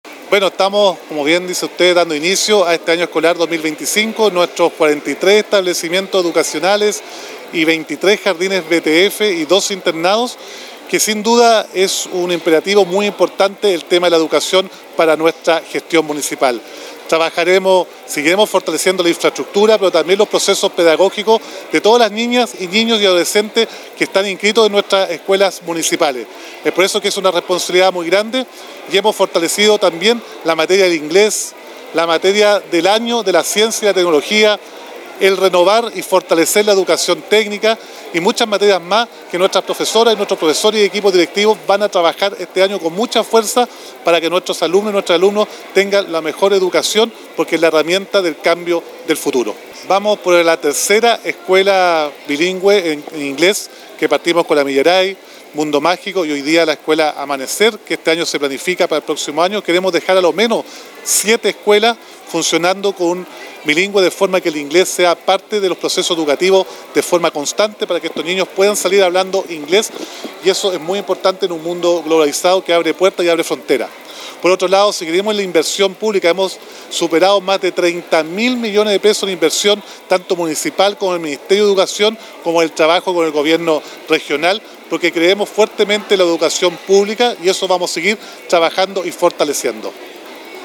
Con una ceremonia realizada en el patio techado del Complejo Educacional Amanecer, Temuco dio inicio oficial al año escolar 2025, declarado como el «Año de las Ciencias y la Tecnología» para la educación pública de la comuna.
Roberto-Neira-Alcalde-de-Temuco-2.mp3